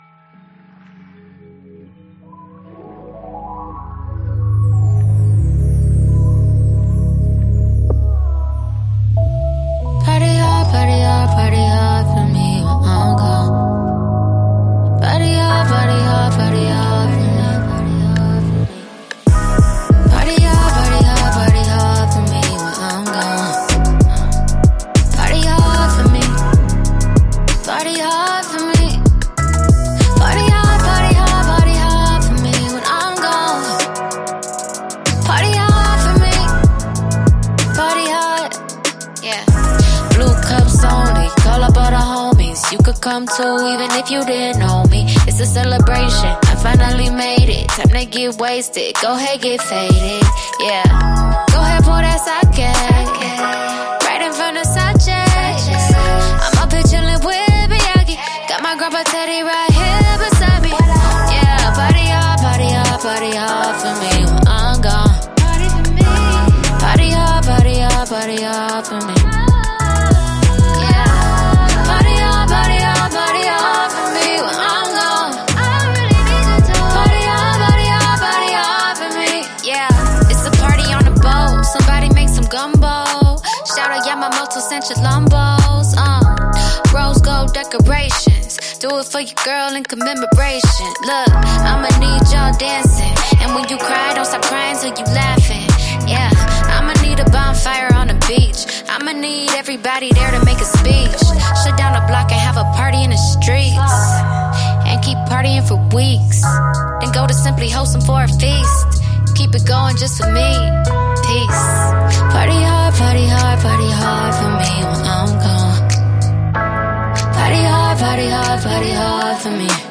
Genre R&B